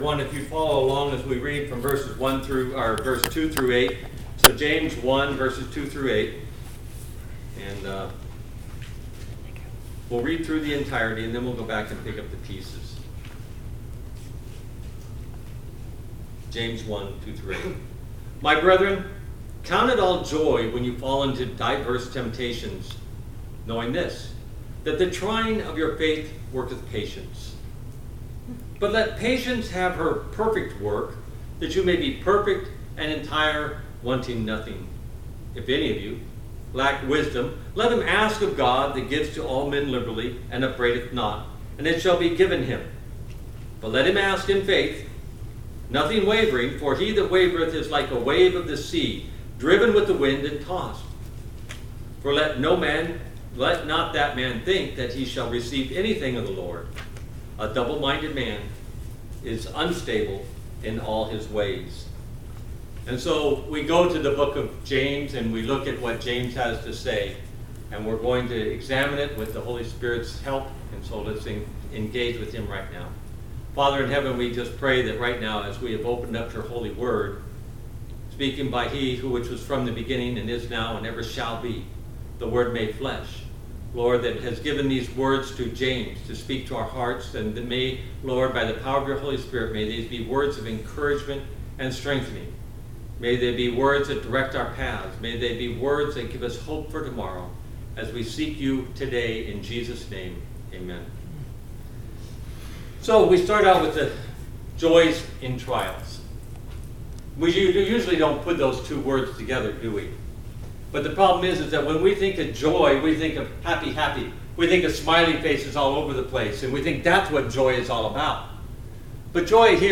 All Sermons Why Trails James 1 2-8 18 September 2022 Series: Wait on The Lord Topic: Waiting through trials Book